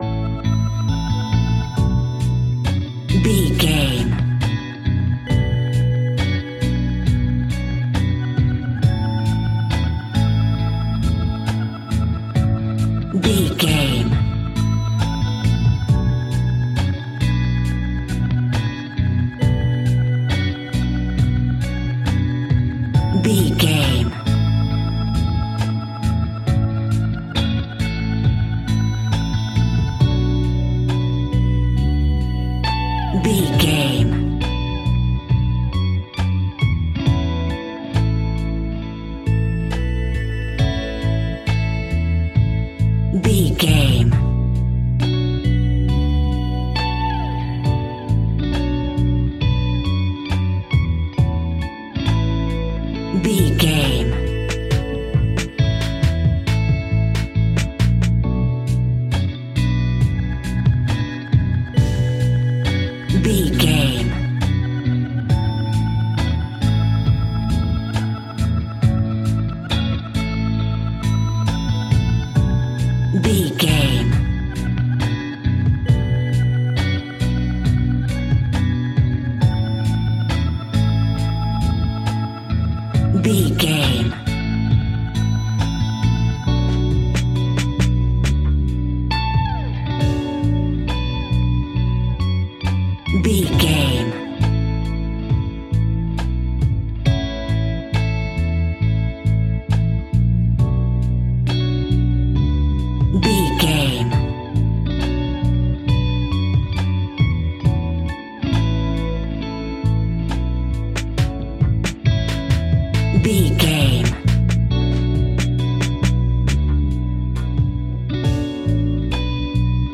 Ionian/Major
A♭
chilled
laid back
Lounge
sparse
new age
chilled electronica
ambient
atmospheric
instrumentals